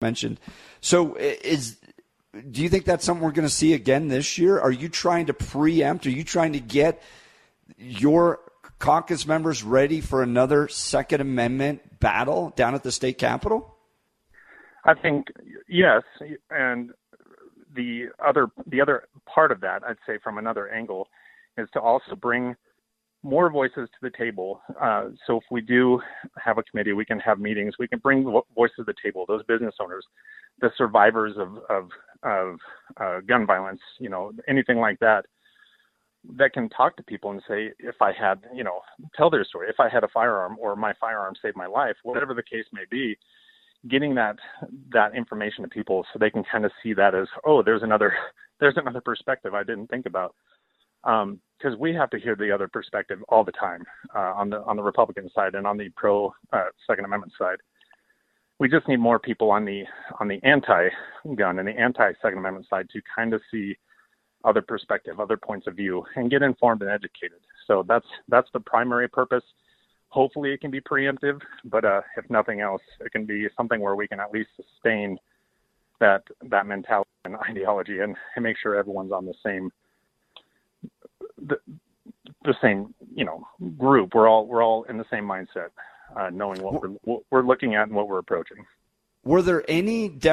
It went poorly, despite Armagost’s enthusiasm: